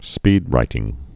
(spēdrītĭng)